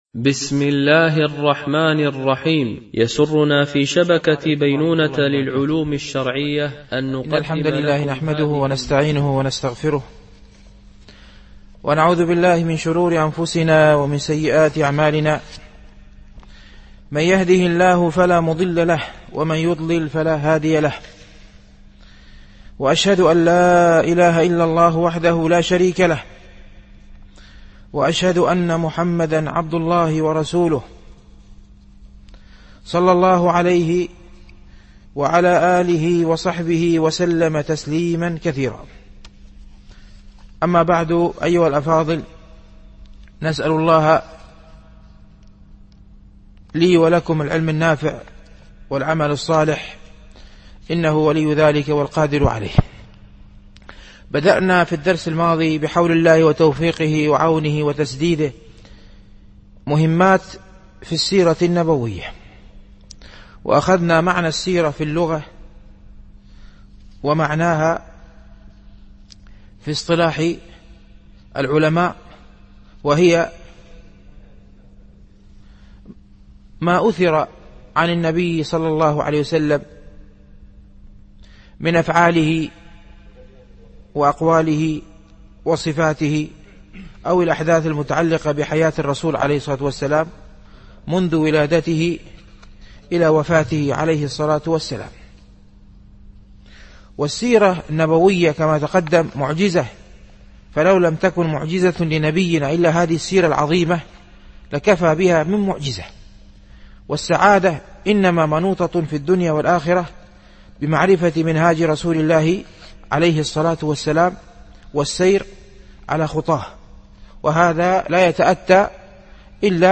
مهمات في السيرة ـ الدرس الثاني